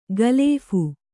♪ galēphu